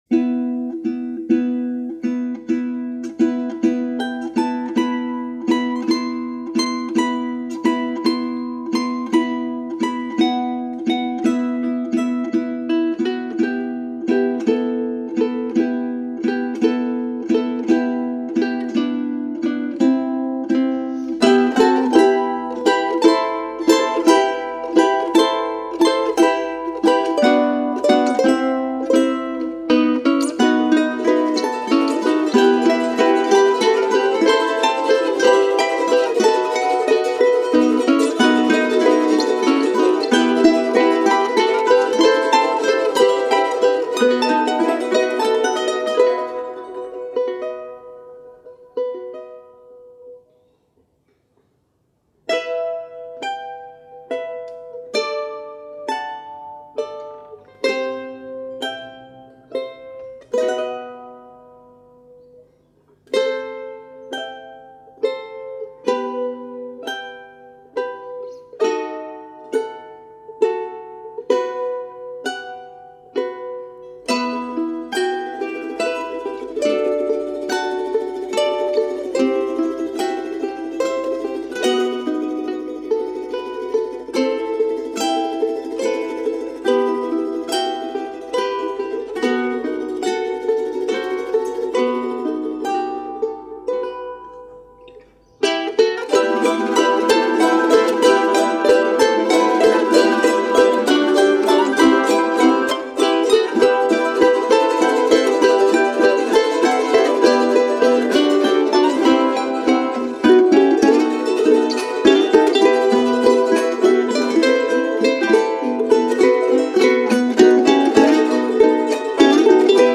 ARGENTINA – INTERPRETE
izq. tocando el charango